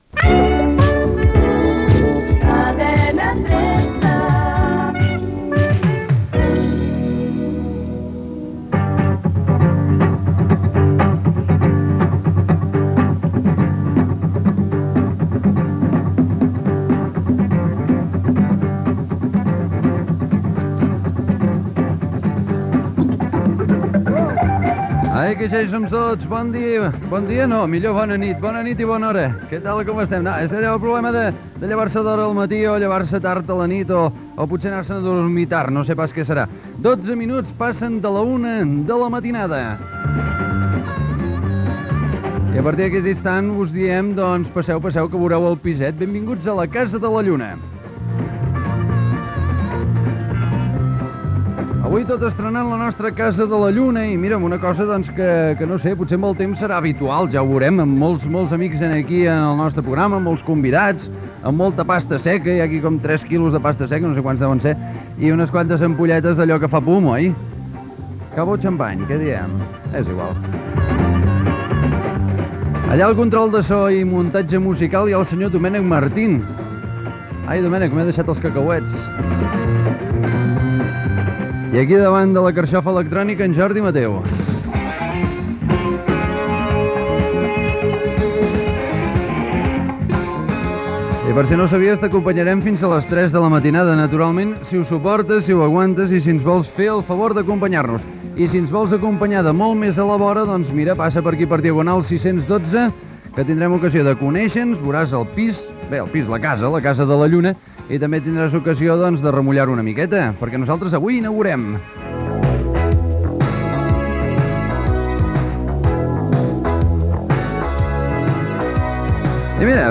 Indicatiu de la cadena, hora, presentació del primer programa, equip, adreça de l'emissora, tema dedicat a la lluna, trucades de l'audiència amb participació d'un astròleg
Entreteniment